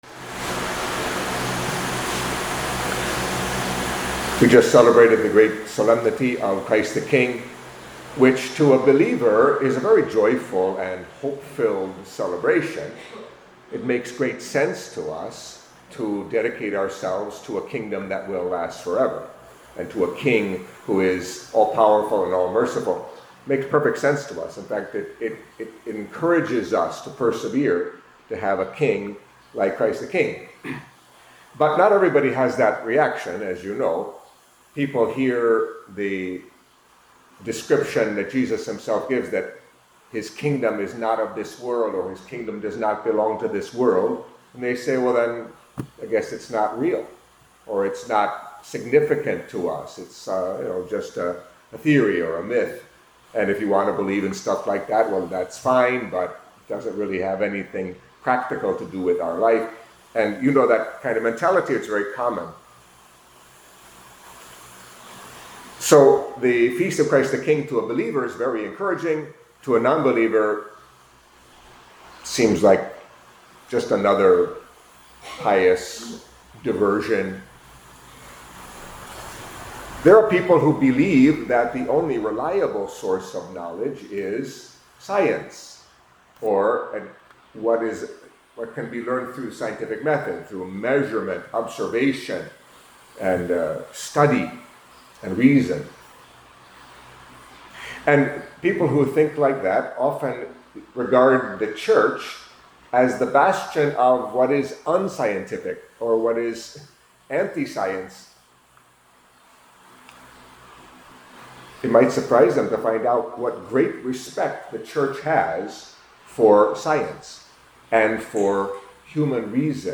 Catholic Mass homily for Monday of the Thirty-Fourth Week in Ordinary Time